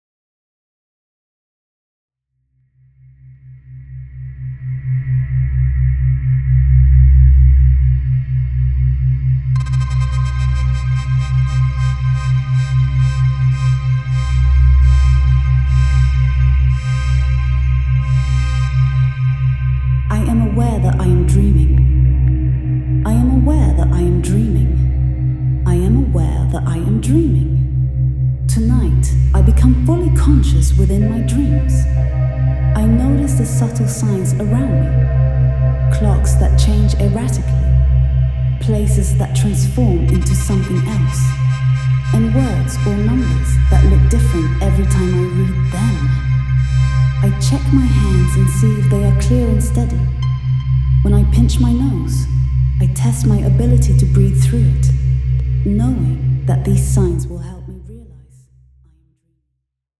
Preview Theta in C